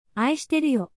Some have built-in audio pronunciation while for others, you can click on the link to hear the pronunciation on Forvo or from a native speaker on YouTube.